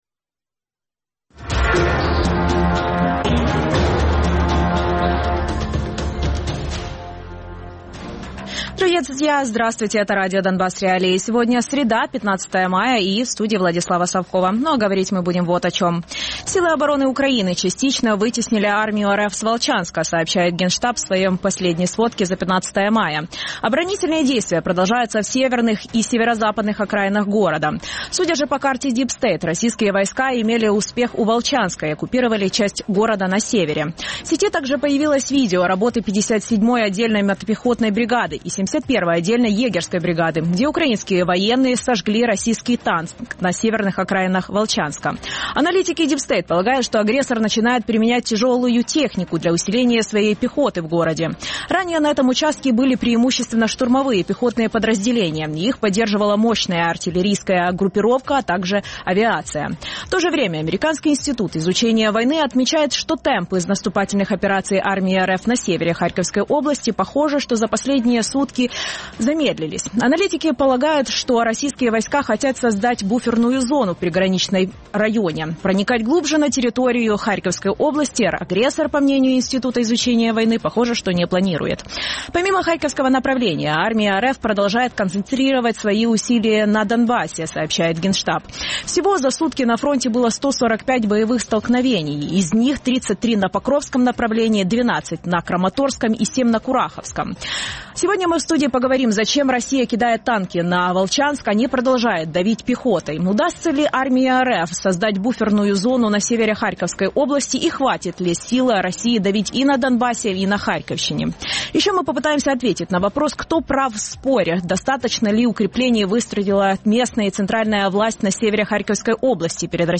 Радіопрограма «Донбас.Реалії» - у будні з 16:30 до 17:30. Без агресії і перебільшення. 60 хвилин найважливішої інформації про війну Росії проти України.